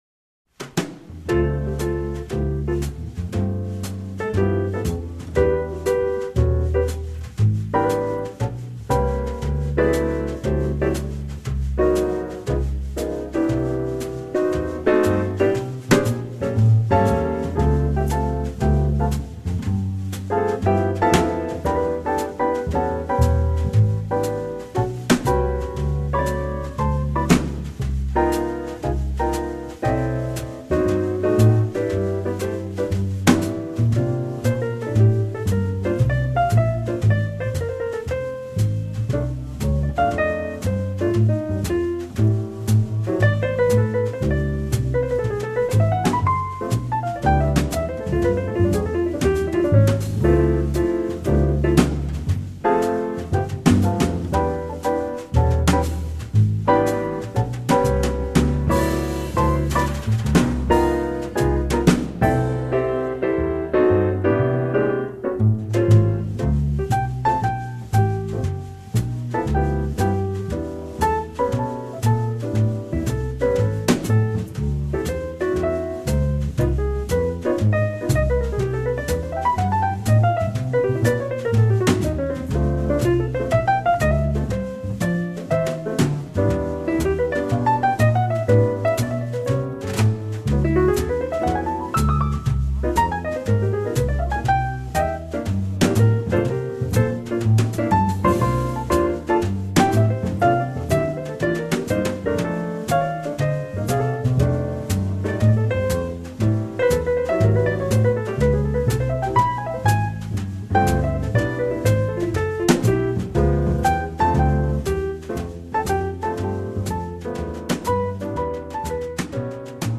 Jazz Trio mit Grand Piano/Jazz Trio with grand piano